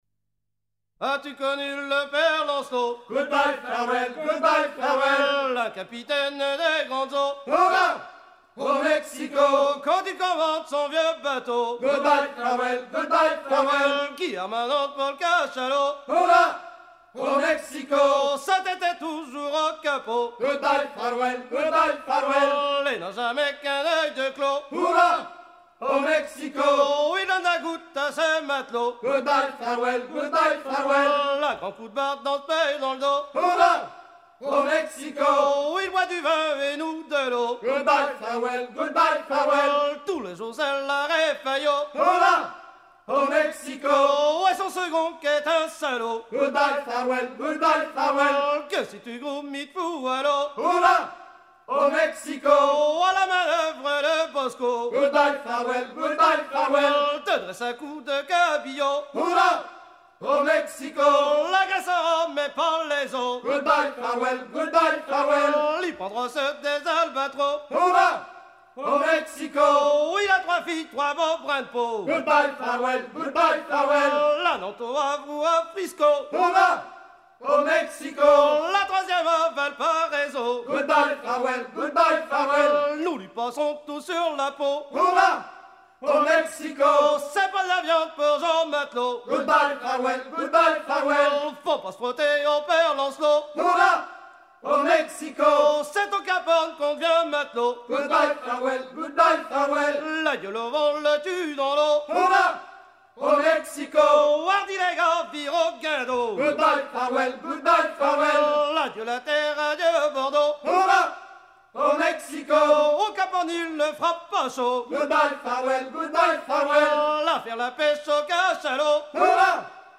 Fonction d'après l'analyste gestuel : à hisser à grands coups
Genre laisse
Catégorie Pièce musicale éditée